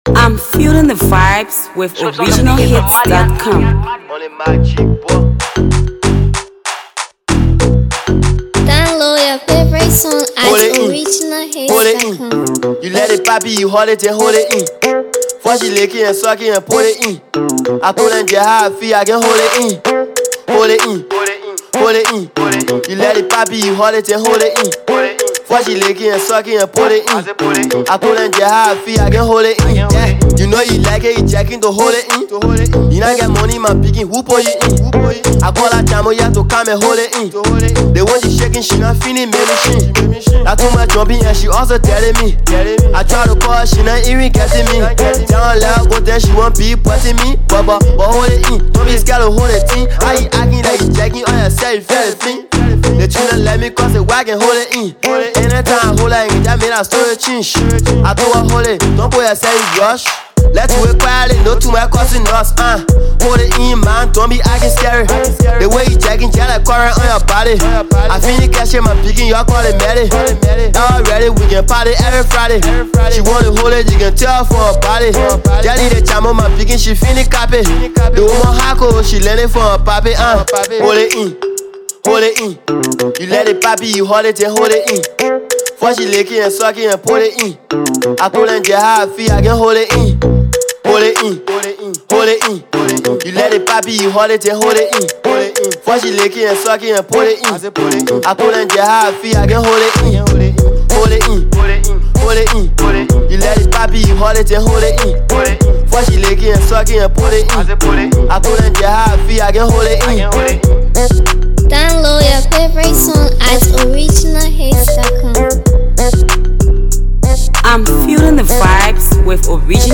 Talented Liberian uprising rapper
trap piece